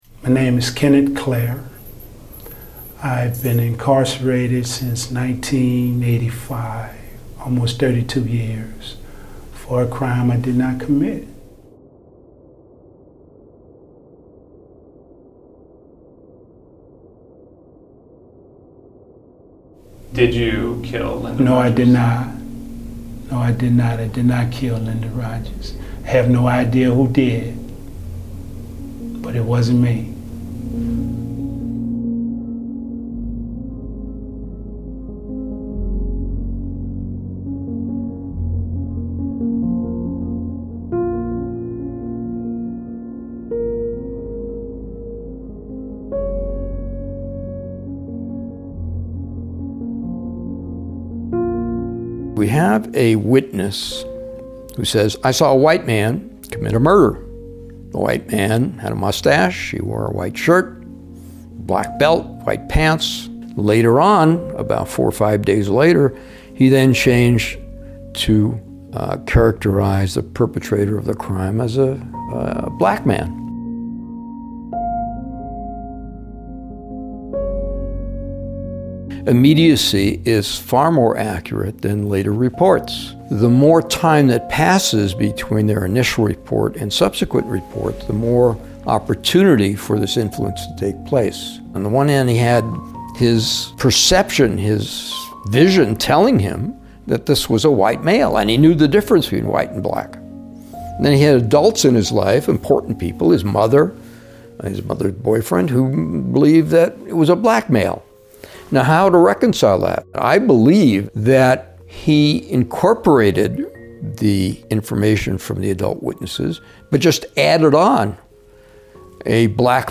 video interview